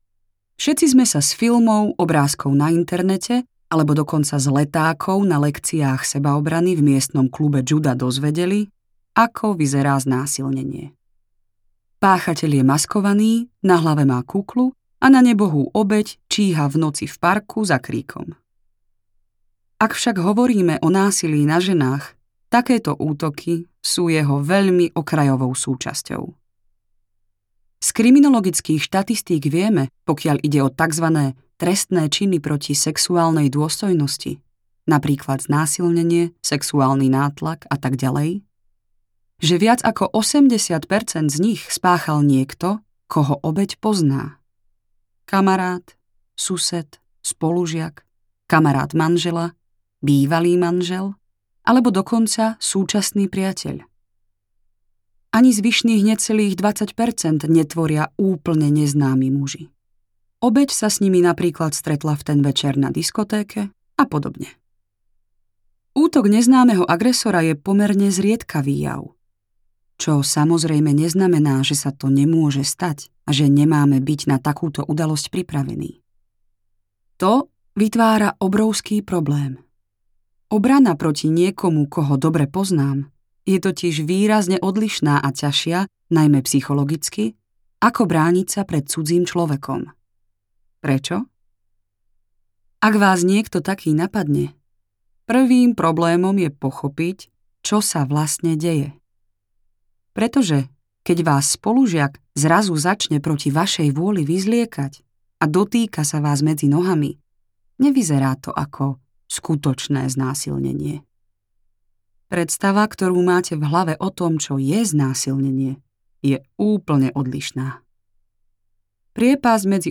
Moderná sebaobrana audiokniha
Ukázka z knihy